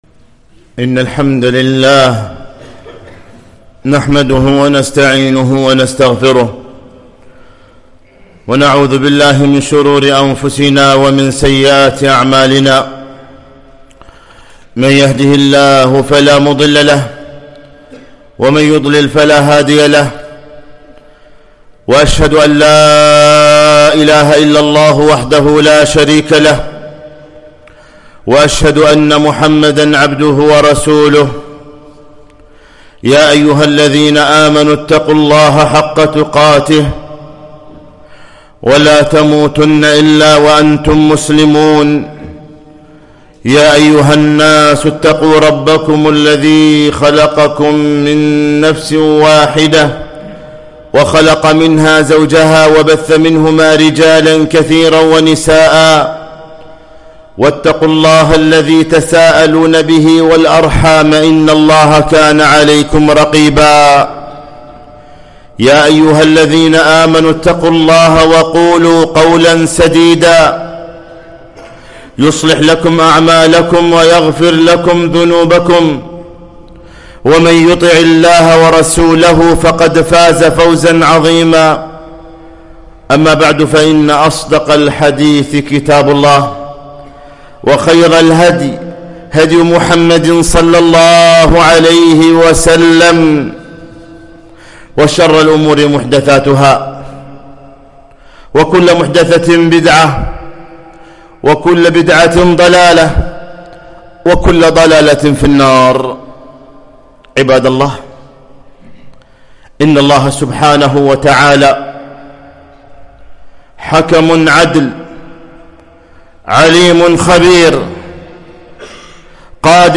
خطبة - ( وما نرسل بالآيات إلا تخويفا)